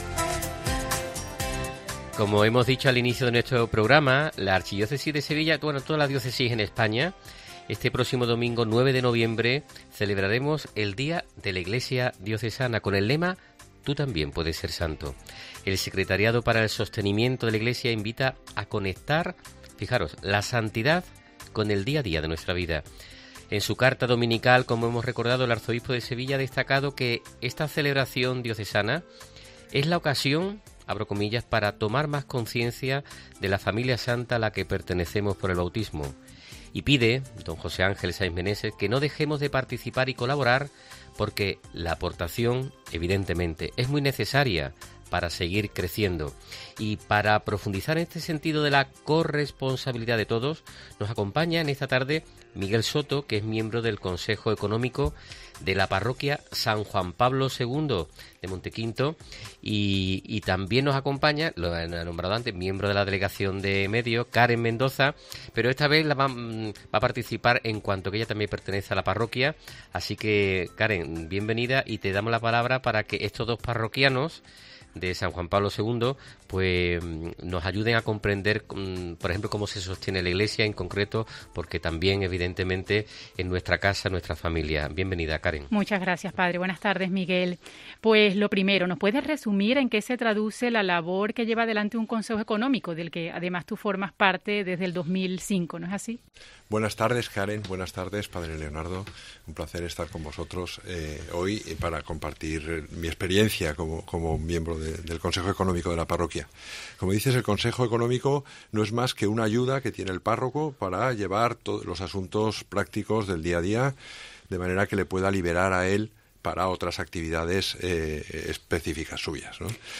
📝 Puede consultar el dossier de prensa sobre el Día de la Iglesia Diocesana aquí Boletín de cuotas parroquiales 194.26 KB ESCUCHA LA ENTREVISTA